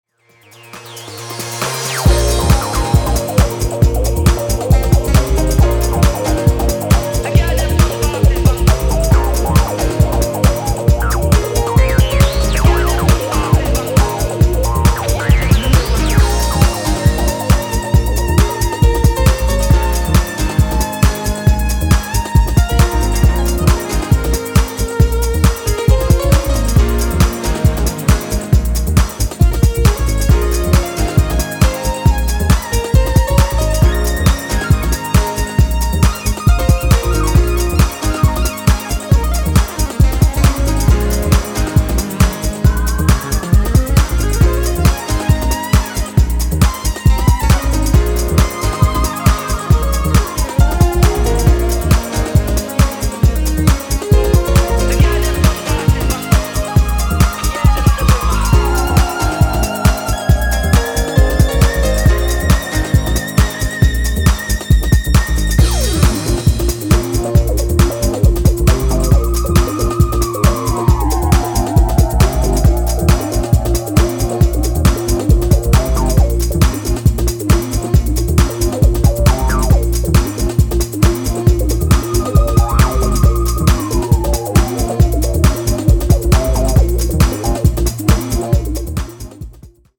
House Techno Bass Breaks